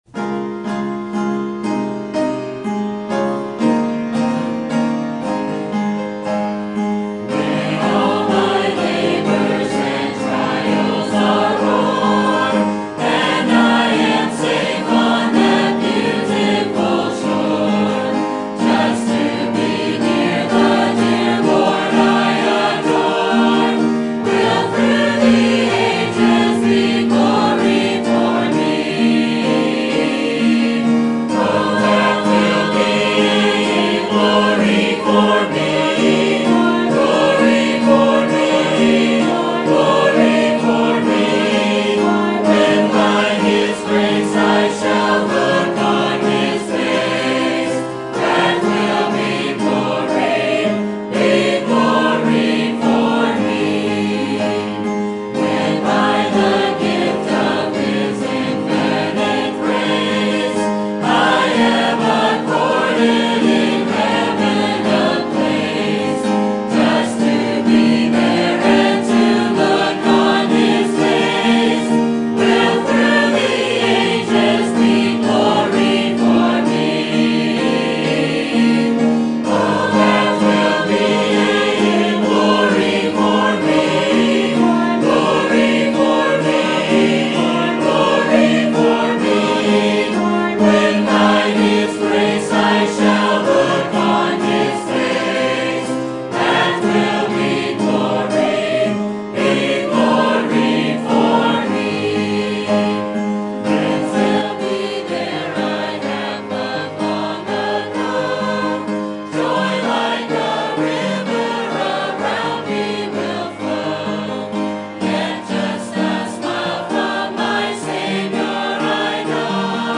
Sermon Topic: Salt and Light Sermon Type: Series Sermon Audio: Sermon download: Download (23.73 MB) Sermon Tags: Matthew Great Laws Salt